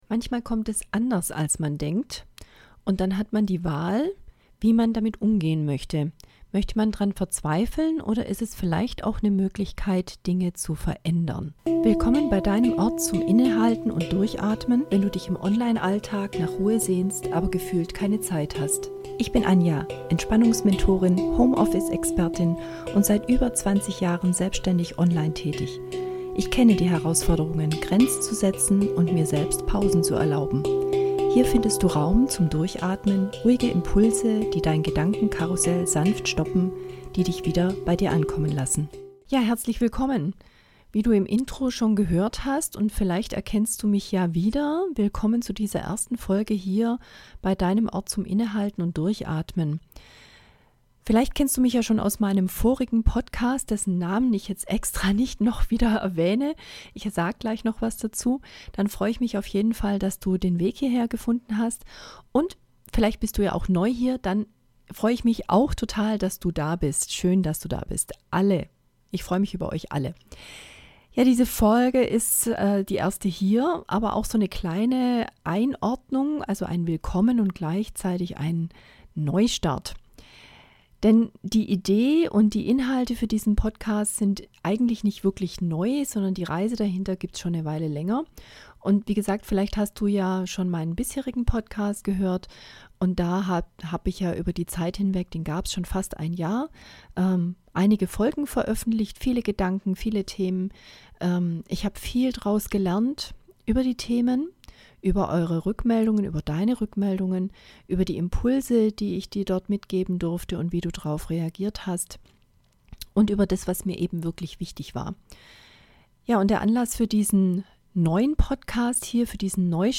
Schultern heben, lösen. Tief einatmen. Langsam ausatmen.